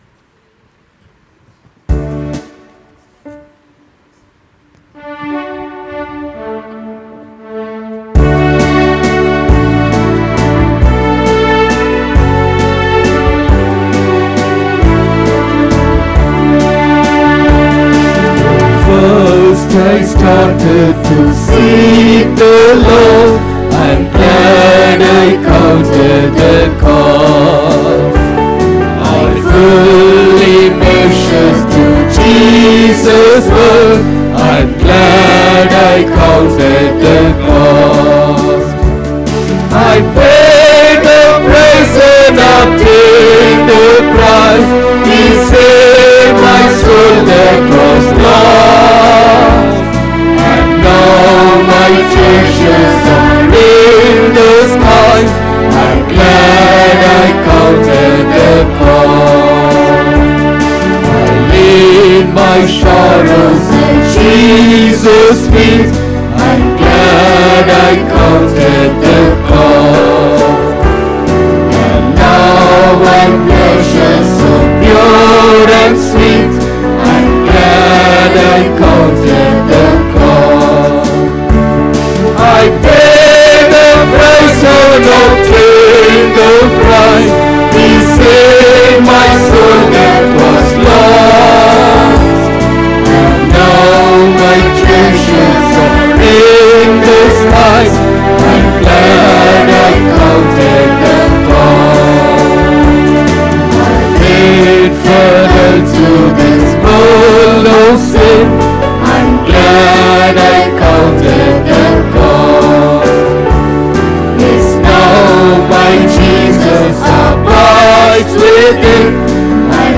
14 May 2023 Sunday Morning Service – Christ King Faith Mission